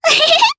Rodina-Vox_Happy3_jp.wav